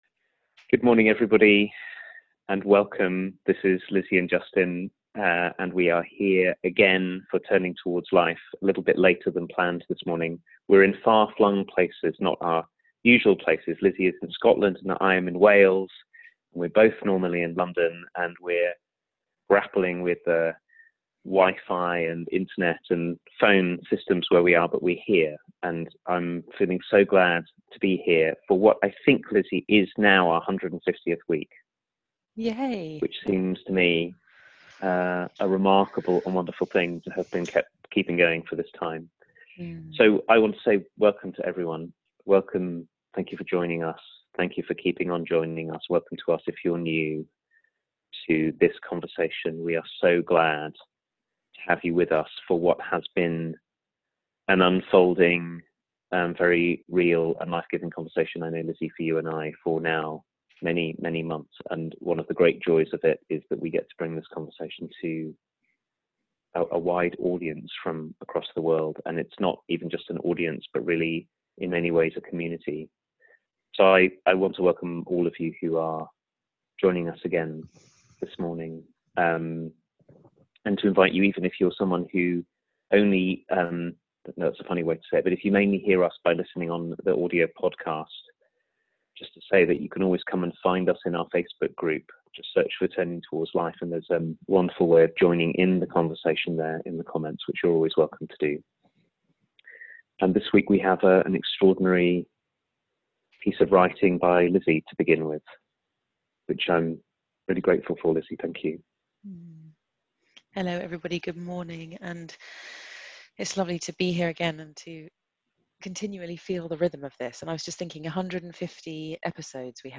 weekly live 30 minute conversation